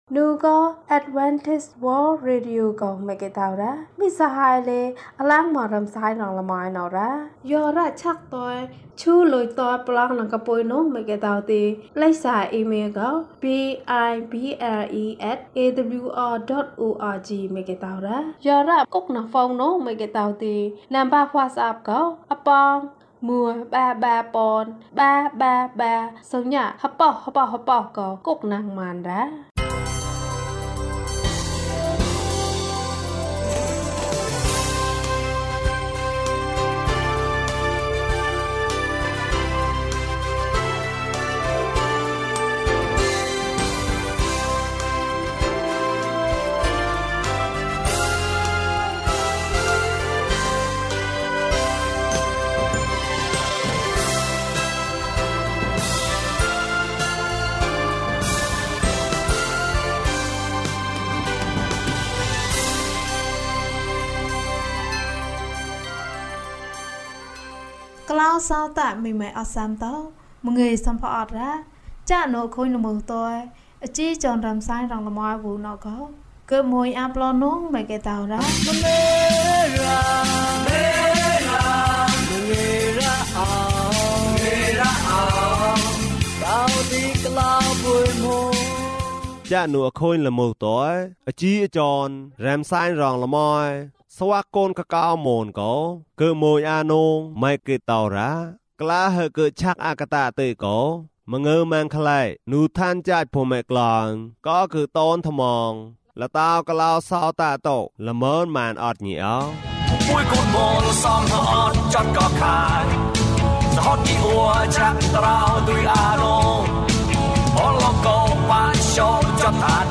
အပြစ်။၀၁ ကျန်းမာခြင်းအကြောင်းအရာ။ ဓမ္မသီချင်း။ တရားဒေသနာ။